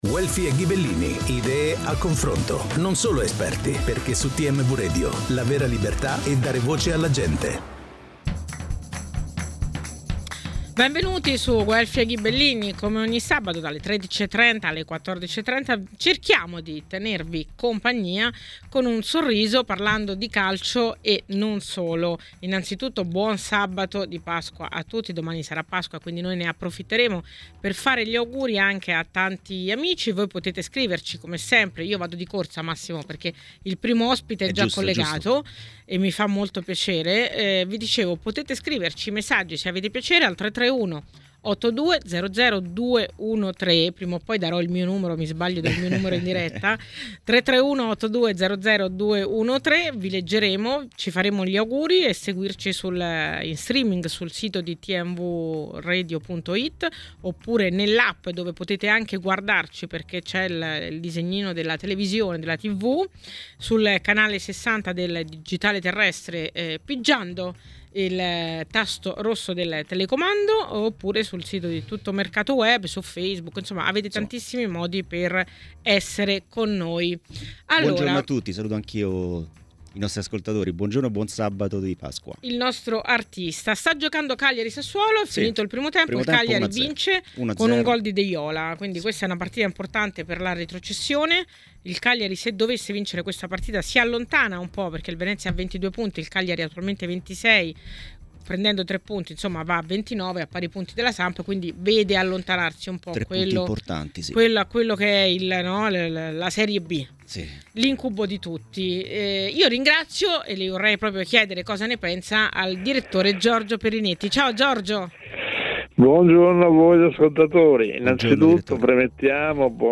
Le Interviste